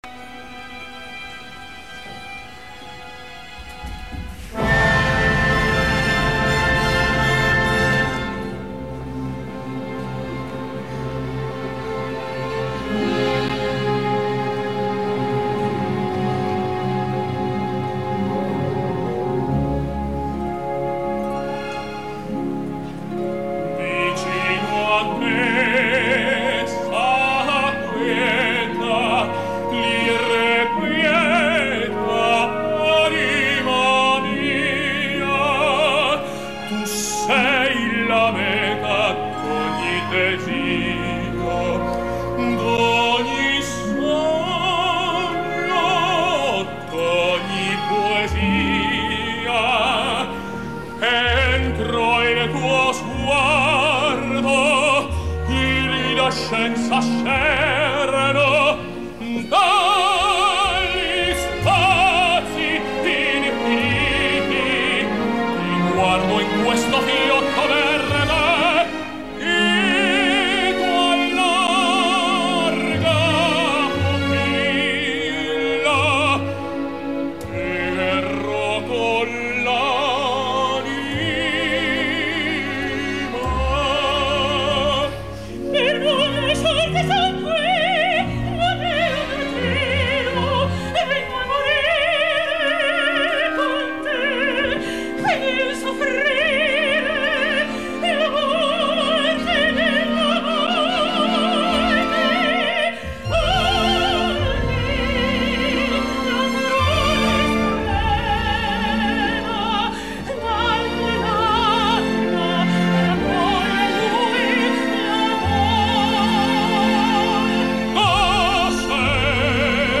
Escolteu ara l’eixelebrat duo final amb Maria José Siri.
Andrea Chénier, Marcelo Álvarez
Maddalena di Coigny, Maria José Siri
Torino, Teatro Regio, 20/1/2013
duo-final.mp3